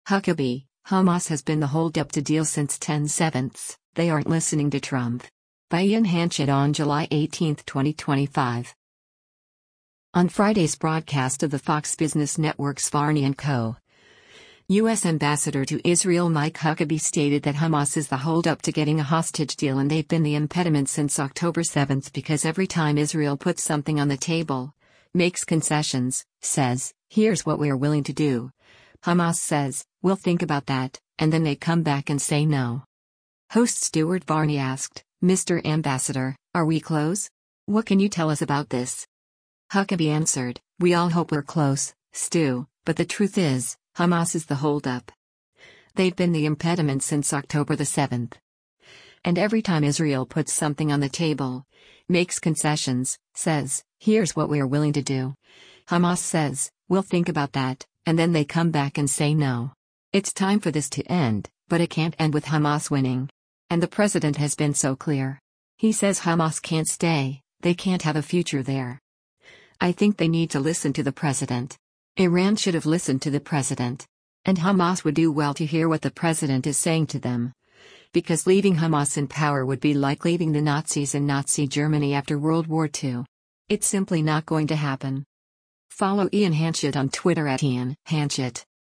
Host Stuart Varney asked, “Mr. Ambassador, are we close? What can you tell us about this?”